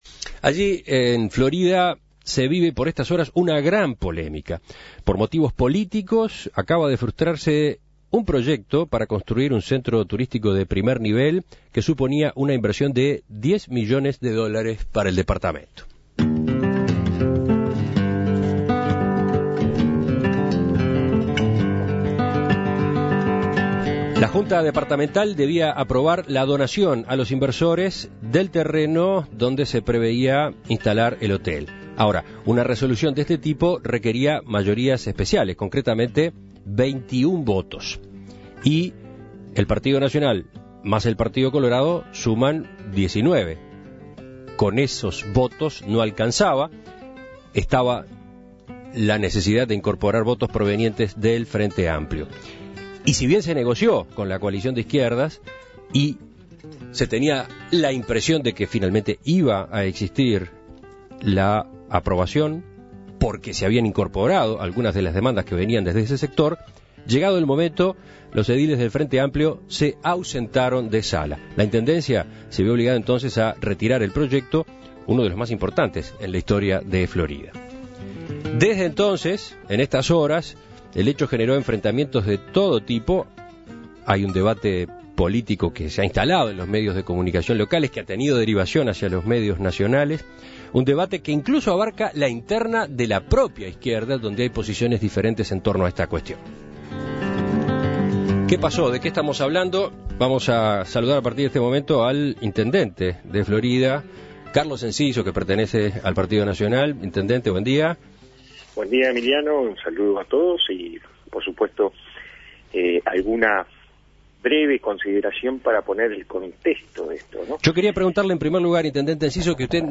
Entrevista con Carlos Enciso (audio)